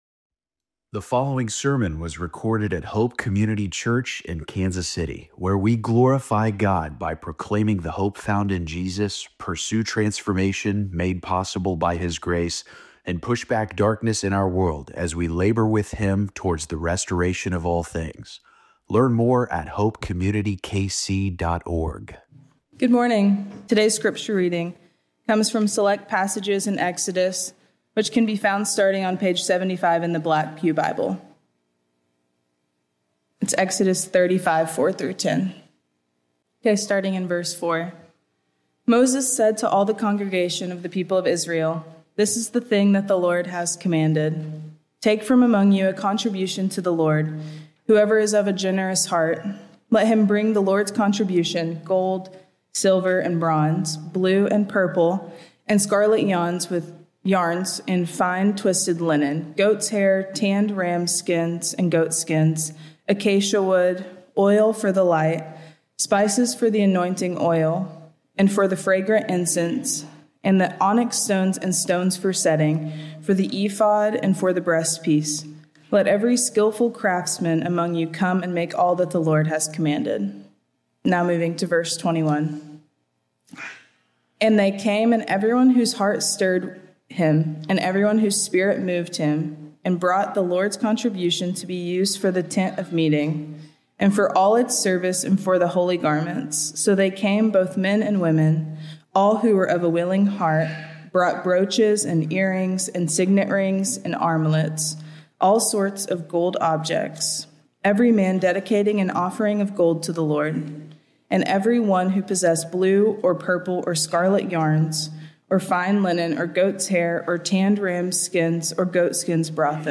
Weekly sermons from Hope Community Church in the Kansas City area.
Hope Community Church Sermons